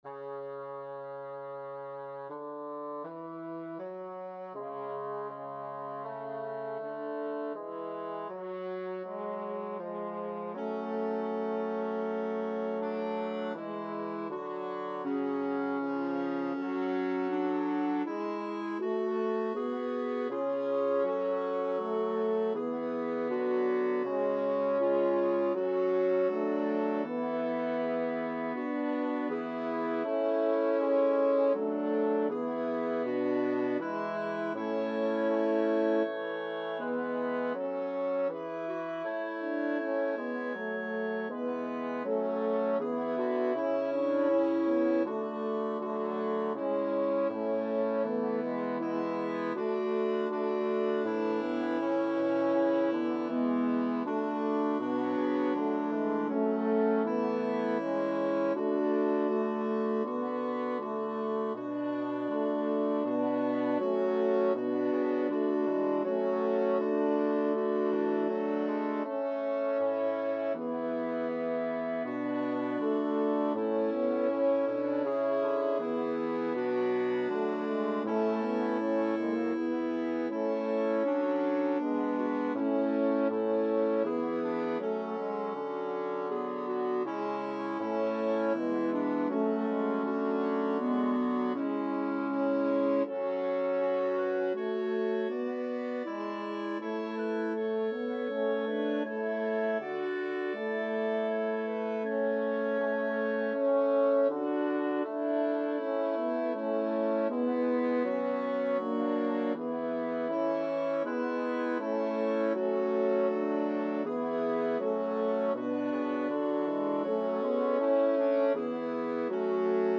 Number of voices: 5vv Voicings: SSATB or AATTB Genre: Sacred, Motet
Language: Latin Instruments: A cappella
Score information: A4, 7 pages, 147 kB Copyright: CPDL Edition notes: Transposed down a minor third for AATTB.